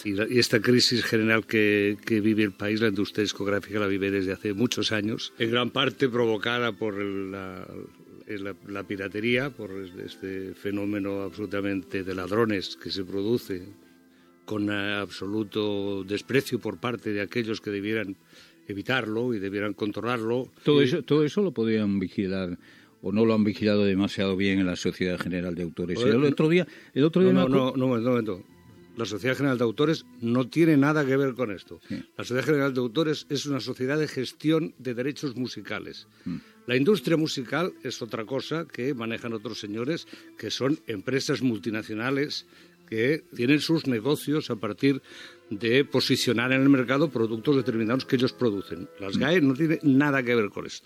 Fragment d'una entrevista al cantautor Joan Manuel Serrat
Info-entreteniment